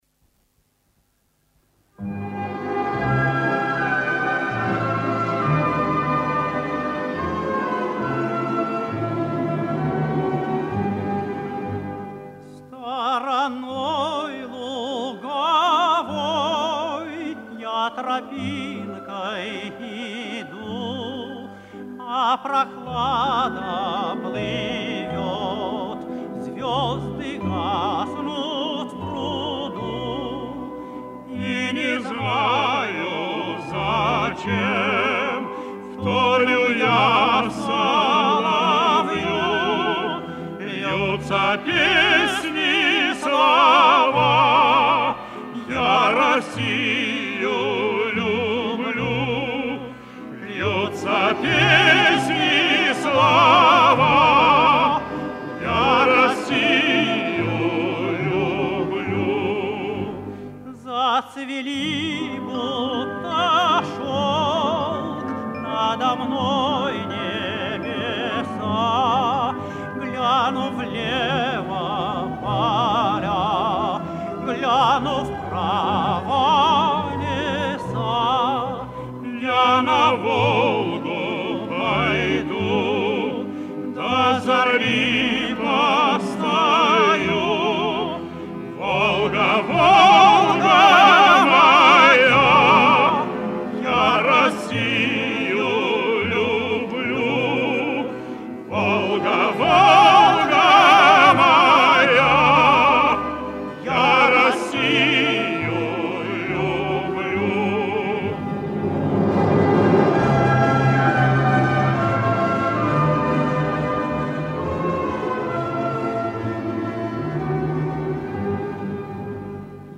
Лиричная и распевная песня от классика жанра.